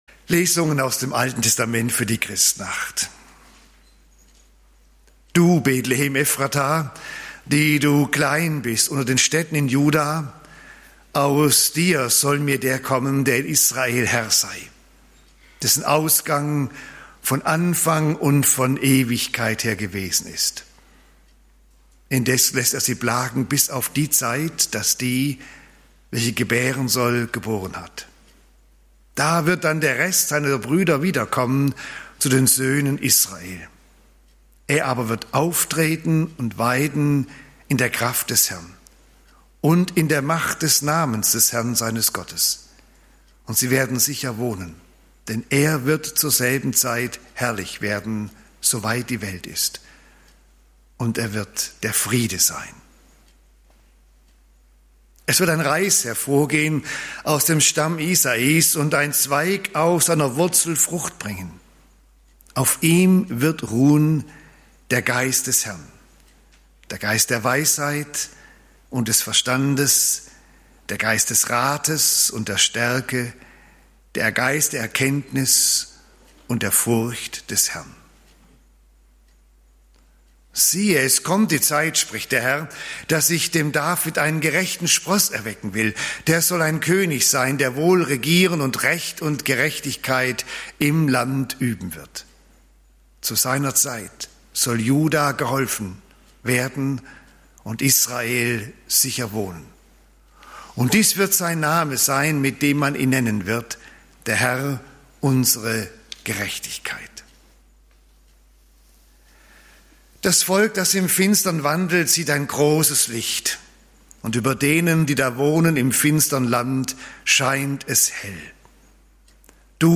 Weihnachten bei Johannes - Der Anfang (Joh. 1, 1-8) - Gottesdienst Christmette